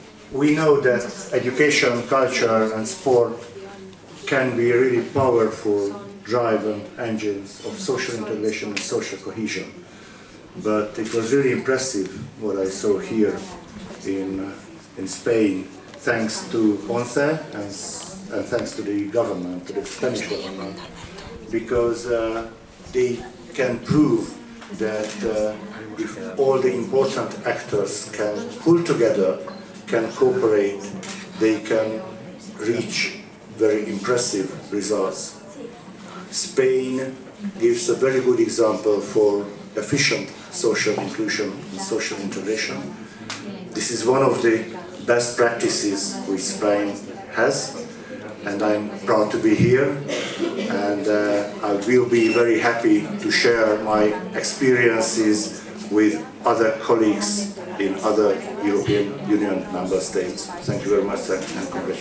“el modelo formativo de la ONCE es un ejemplo a exportar a toda la Unión Europea” (Archivo MP3, intervención en inglés de Navracsics), tras visitar -el 21 de octubre- el Centro de Recursos Educativos de la Organización en Madrid.